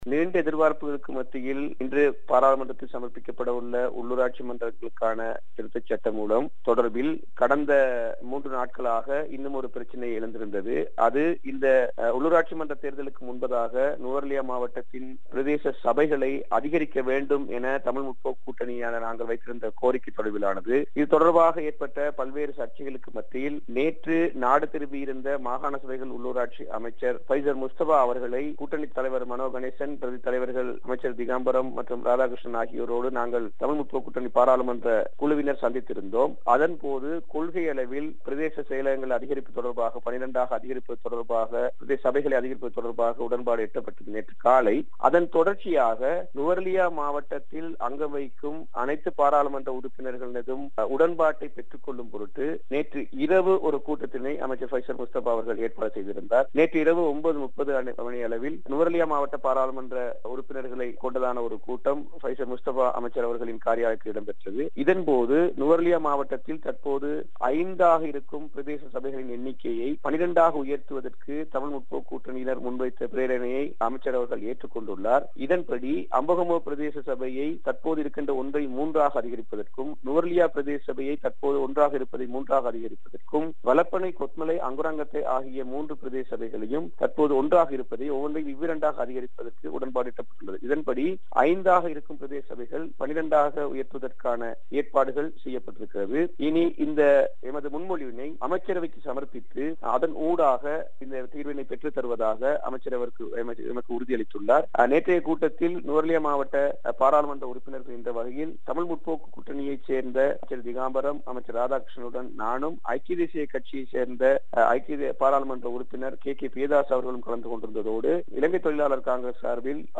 நுவரெலியா மாவட்ட உள்ளுராட்சி மற்றங்களை அதிகரிக்க நடவடிக்கை (குரல் பதிவு)
இது குறித்த விபரங்களை நாடாளுமன்ற உறுப்பினர் திலக்ராஜ் வழங்குகிறார்.